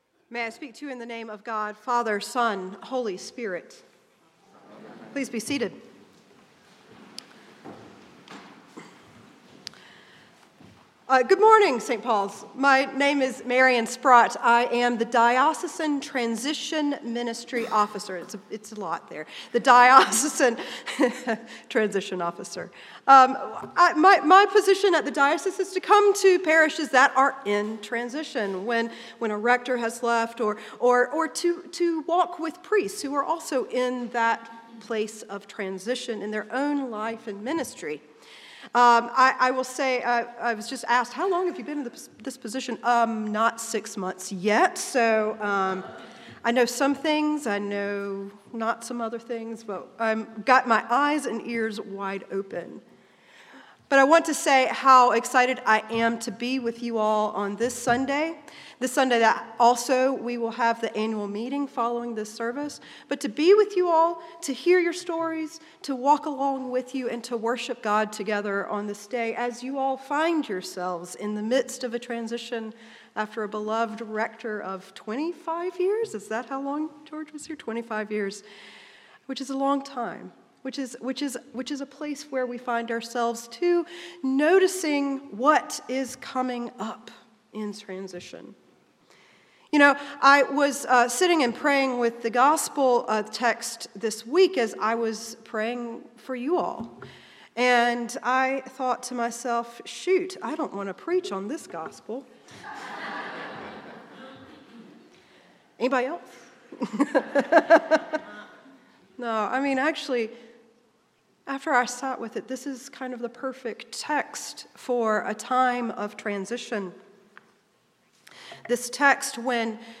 St-Pauls-HEII-9a-Homily-17NOV24.mp3